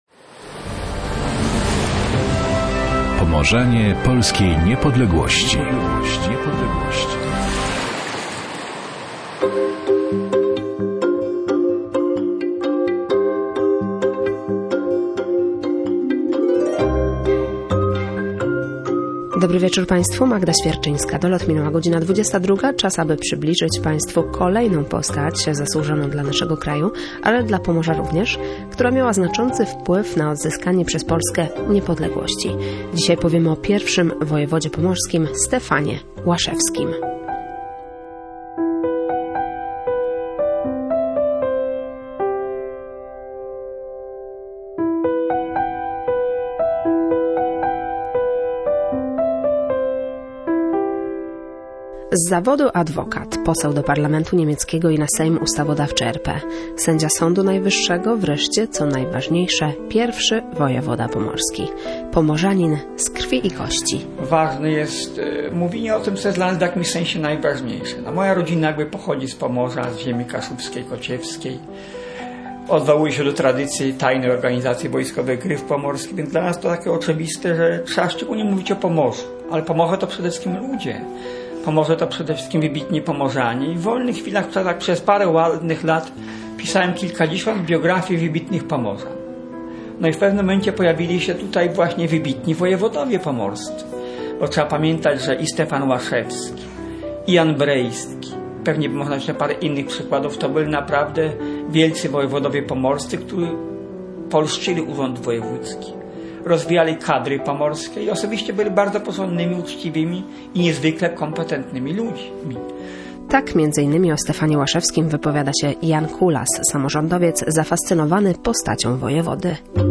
Aby zrealizować audycję, autorka wybrała się do Brąchnówka pod Toruniem, do wsi, w której urodził się Łaszewski.